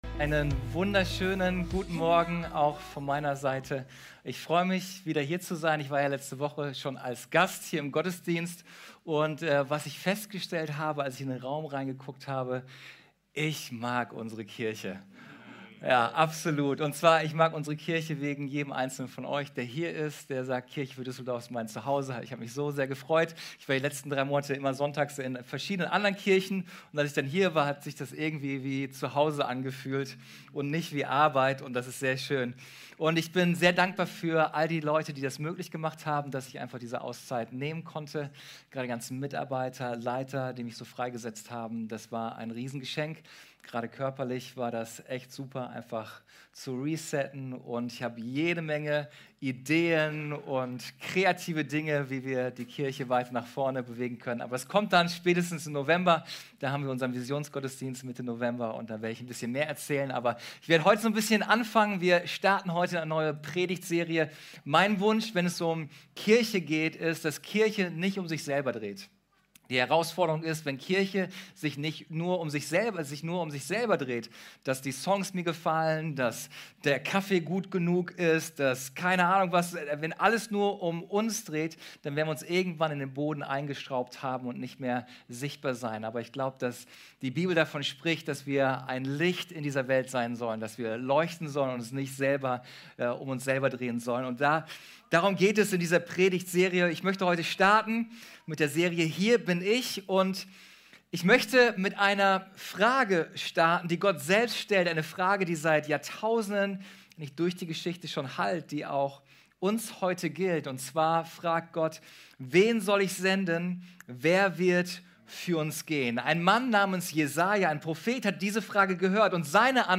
Wir starten in unsere neue Predigtserie "Hier bin ich".